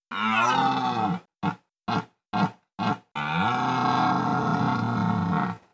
animals